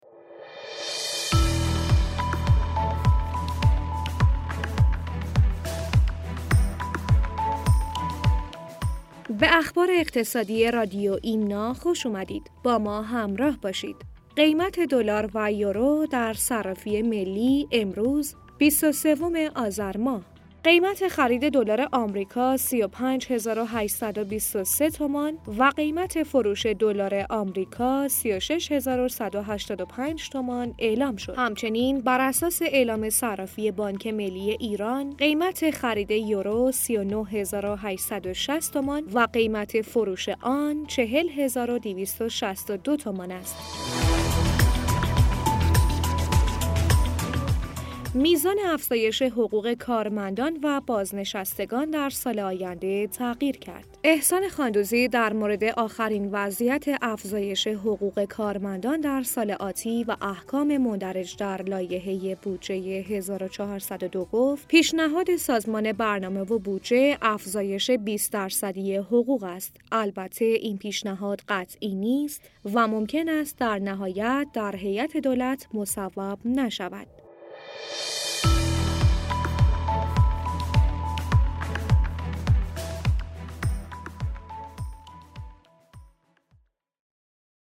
در بسته خبری امروز رادیو ایمنا از افزایش احتمالی ۲۰ درصد حقوق کارمندان و بازنشستگان در سال آینده و همچنین نرخ دلار و یورو امروز چهارشنبه ۲۳ آذر ۱۴۰۱ خواهیم گفت.